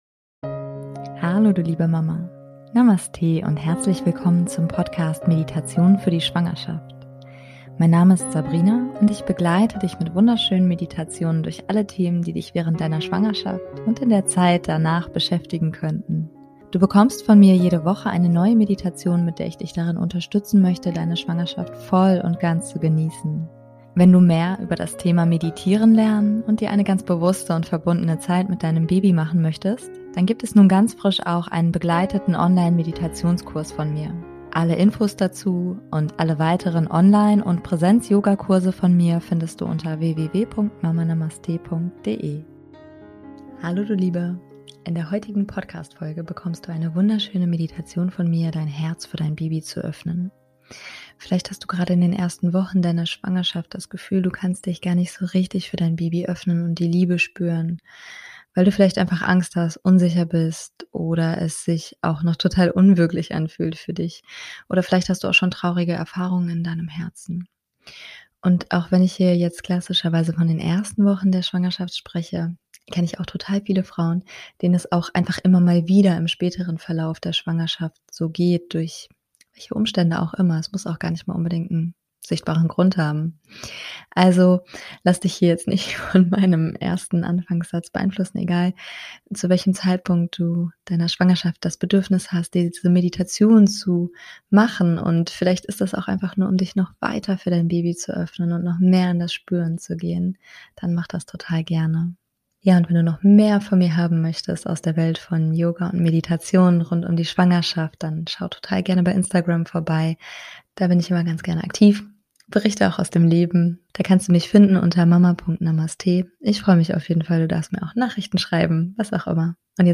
In der heutigen Podcastfolge bekommst du eine wunderschöne Meditation von mir, dein Herz für dein Baby zu öffnen.